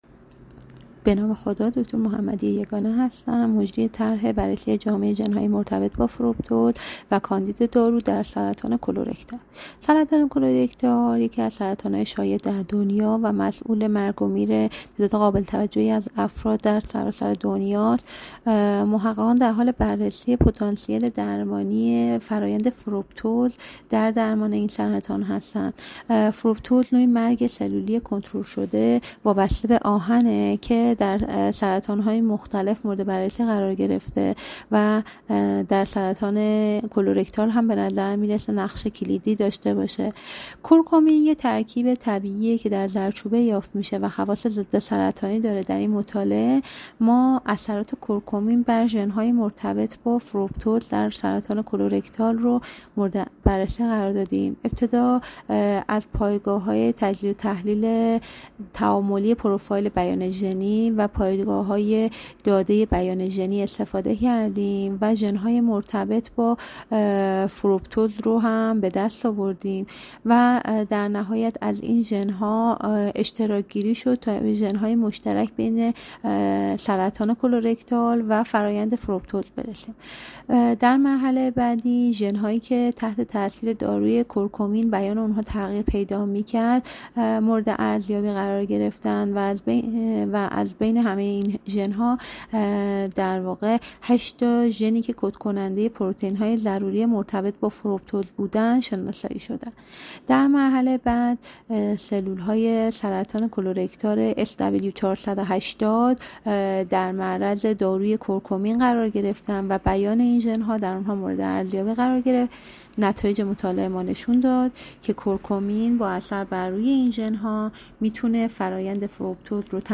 مصاحبه